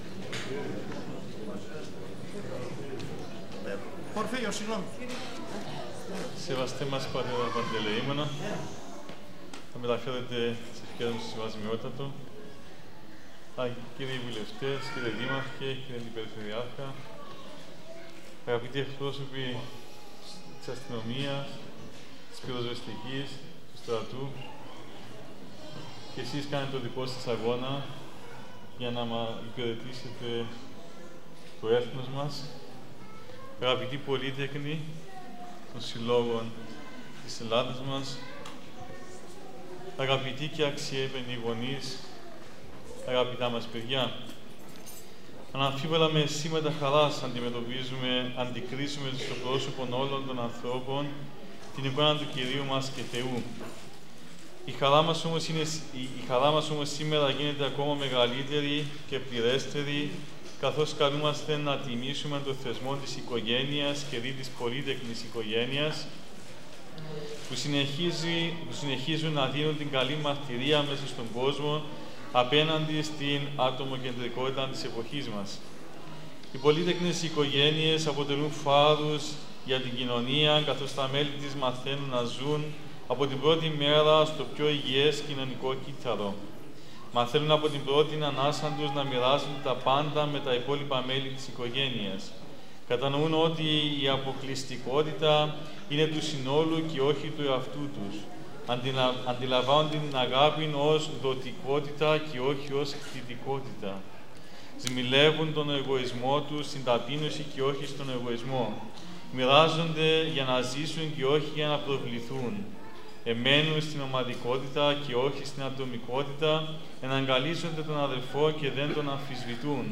Πραγματοποιήθηκε την Κυριακή 29 Σεπτεμβρίου 2024, στο Πνευματικό Κέντρο της Ιεράς Μητροπόλεως Εδέσσης, η ετήσια εκδήλωση του Συλλόγου Πολυτέκνων Εδέσσης «Ο Άγιος Στυλιανός».